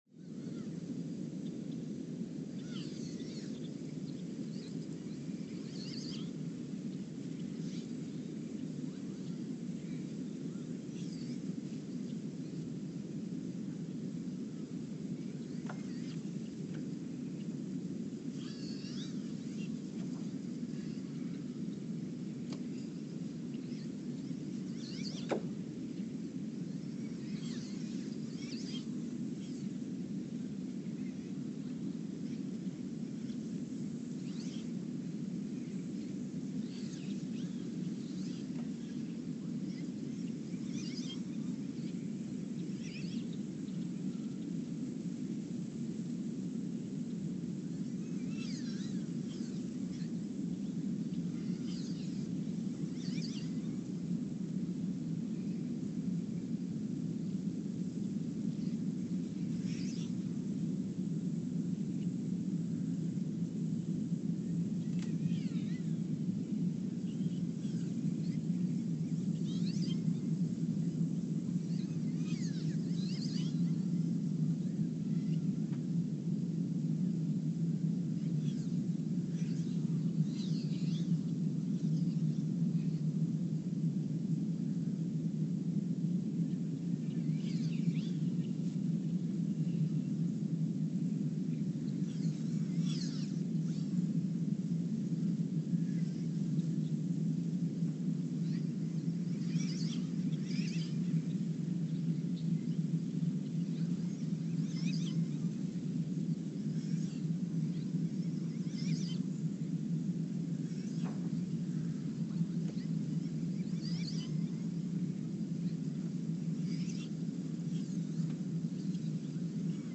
The Earthsound Project is an ongoing audio and conceptual experiment to bring the deep seismic and atmospheric sounds of the planet into conscious awareness.
Speedup : ×900 (transposed up about 10 octaves)
Loop duration (audio) : 11:12 (stereo)